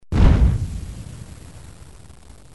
جلوه های صوتی
دانلود صدای بمب و موشک 16 از ساعد نیوز با لینک مستقیم و کیفیت بالا